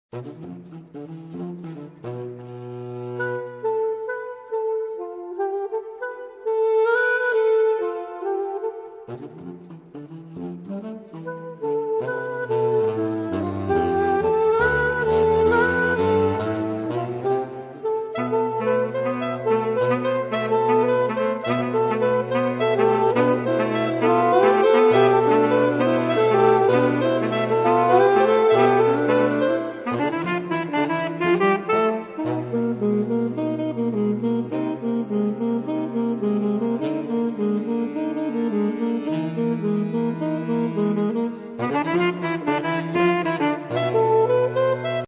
noty pro saxofon
Obsazení: 4 Saxophone (AATBar)